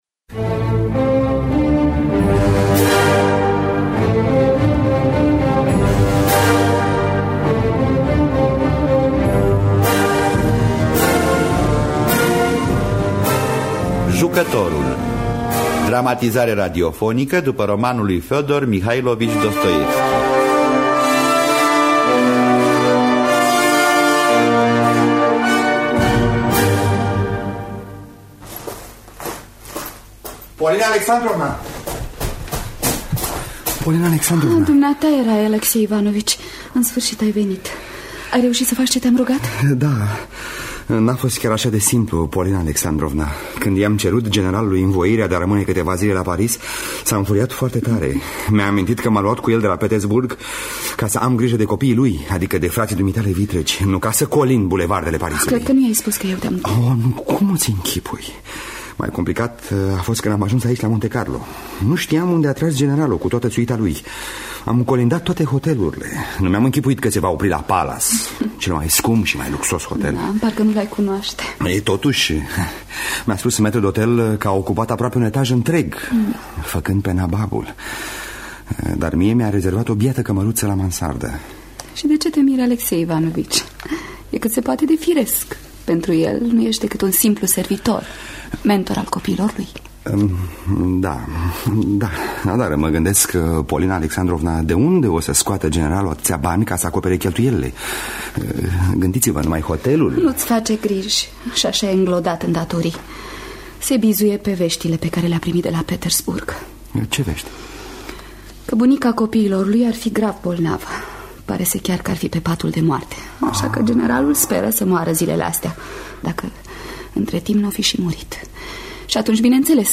Dramatizarea radiofonică de Leonard Efremov.